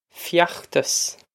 feachtas fyokh-tos
This is an approximate phonetic pronunciation of the phrase.